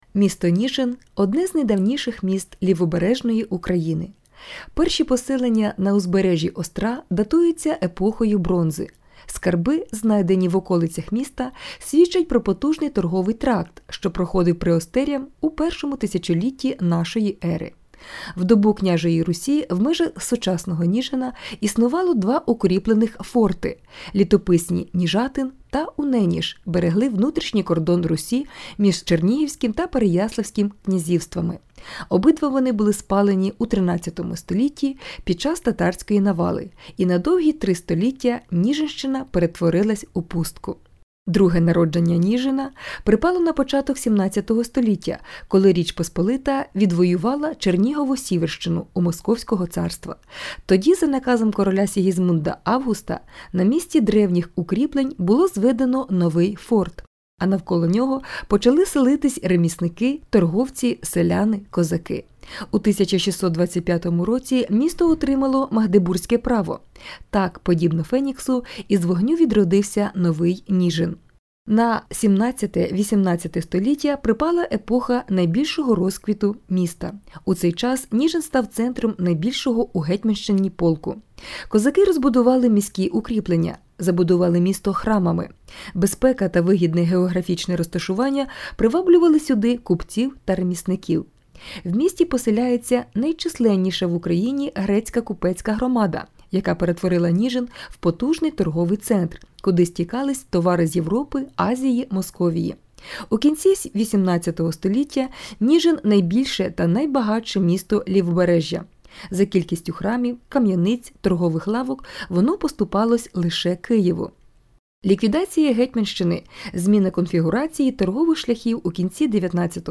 Аудіоекскурсія
Екскурсія містом